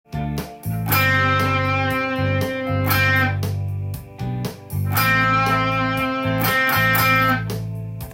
1音で弾くアドリブ
ドの位置だけ覚えてその　ドを連続で弾くことで